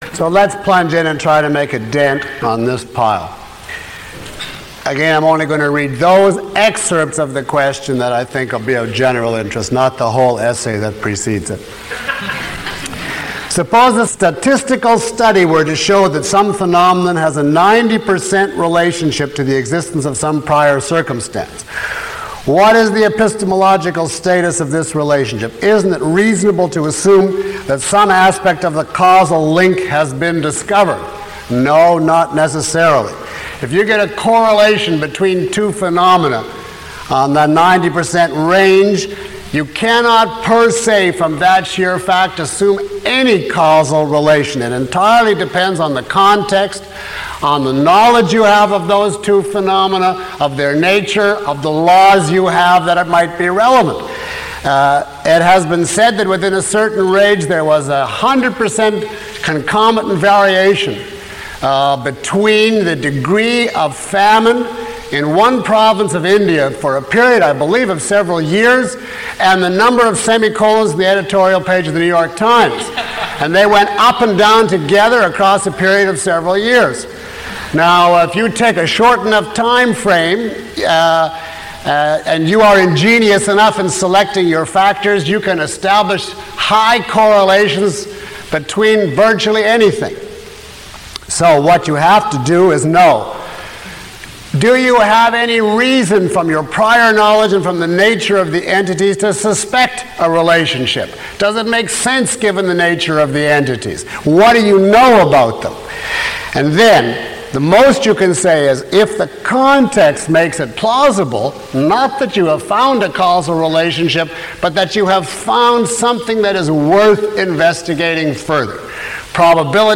A question and answer session for course attendees.
Read more » In this lecture: This is a Q&A session on Objectivism.